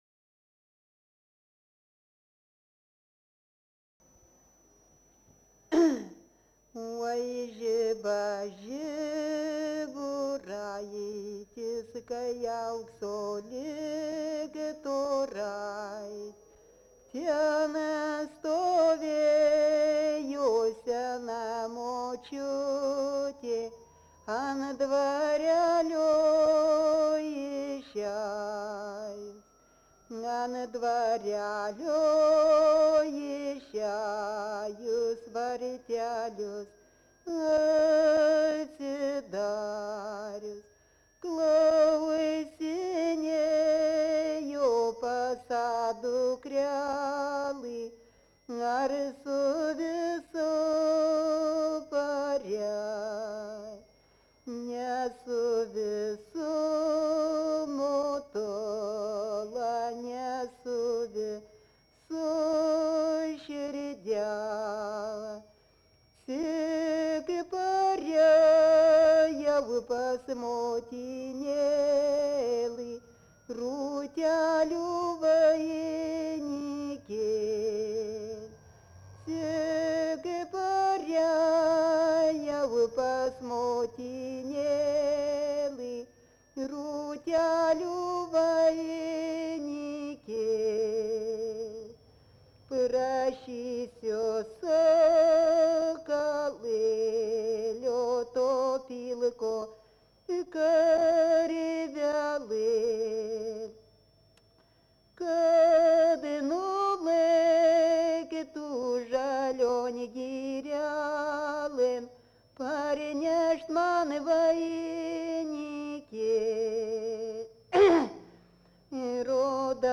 Dalykas, tema daina
Erdvinė aprėptis Dargužiai
Atlikimo pubūdis vokalinis